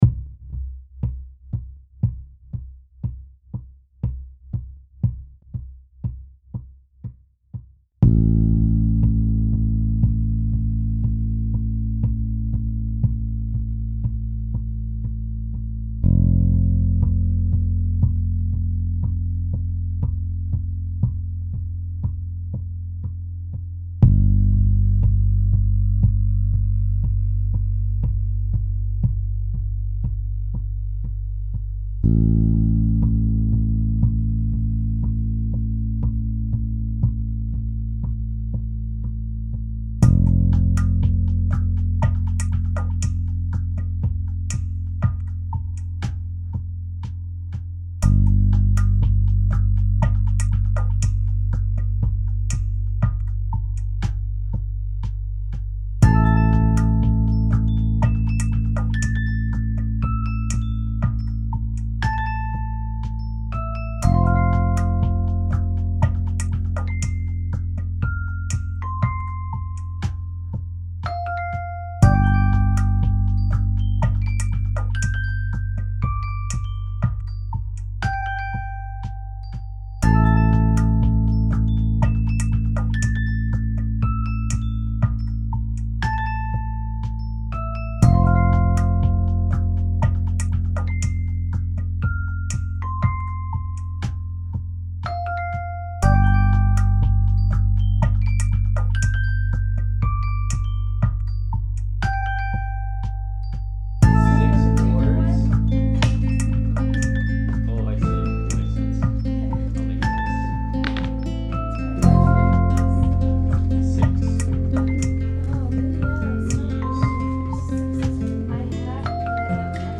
Das war auch der Grund für mich meine Musiksachen im Koffer mitzuschleppen, also MidiController, AudioInterface und Mikro.
Zwei Sachen möchte ich heute mit euch teilen: Zum Einen ein Lied, dass bei einem Spieleabend in Kanada entstanden ist.